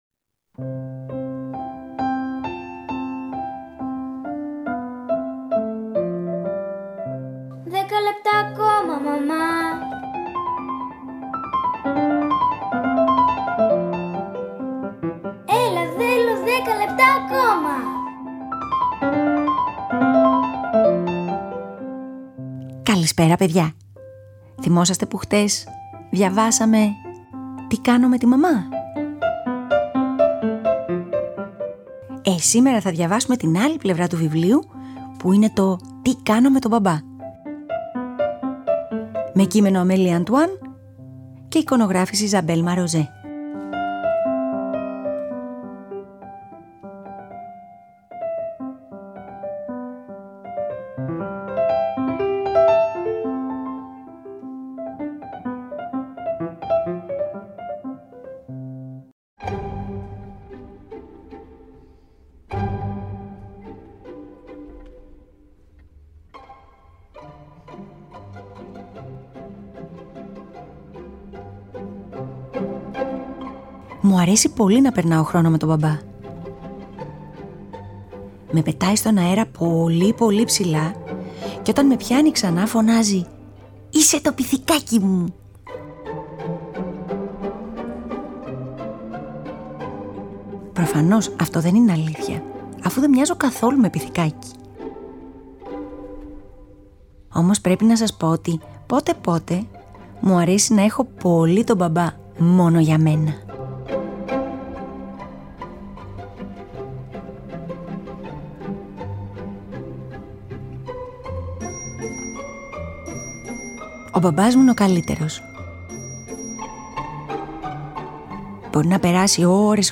Σήμερα διαβάζουμε για το μπαμπά!